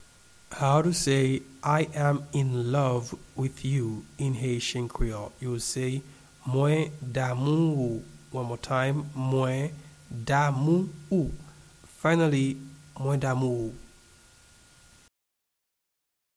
a native Haitian voice-over artist can be heard in the recording here
I-am-in-love-with-you-in-Haitian-Creole-Mwen-damou-ou-pronunciation.mp3